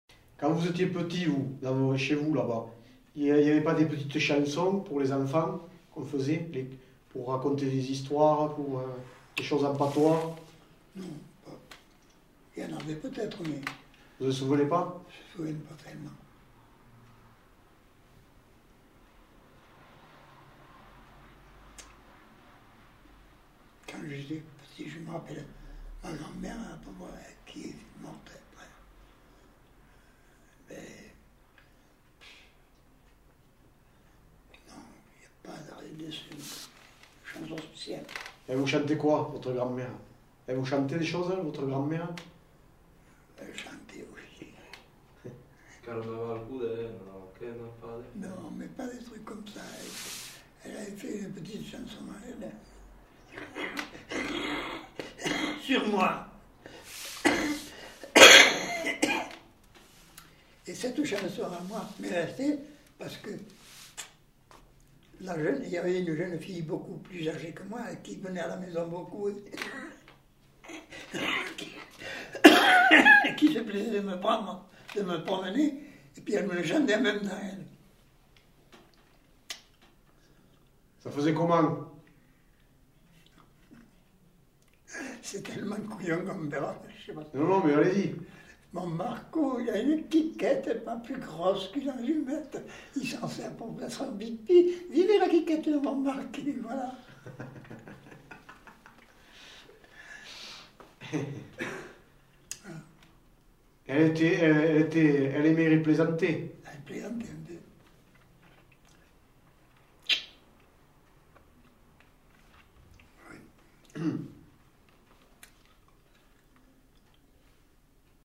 Aire culturelle : Quercy
Lieu : Souillac
Genre : chant
Effectif : 1
Type de voix : voix d'homme
Production du son : chanté
Classification : enfantines diverses